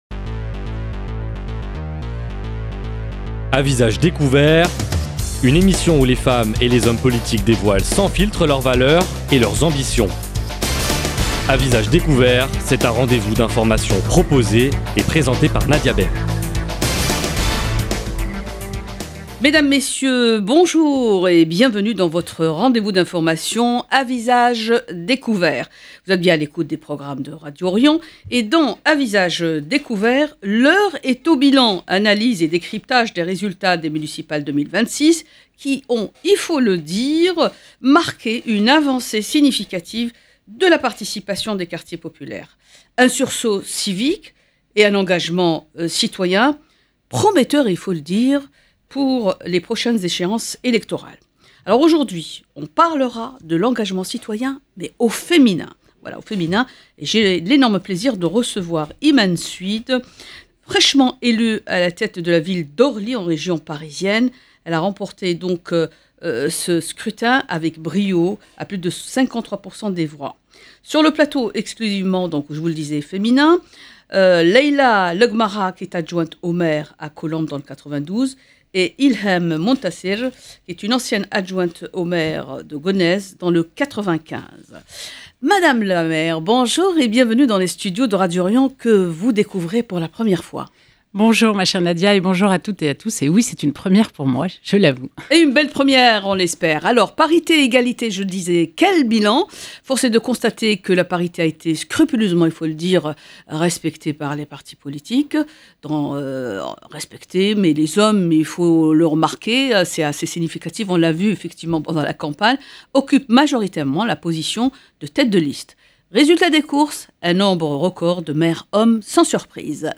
ce débat analyse les dynamiques à l’œuvre, les freins persistants et les leviers nécessaires pour accélérer l’égalité femmes-hommes dans la gouvernance locale. 0:00 44 min 28 sec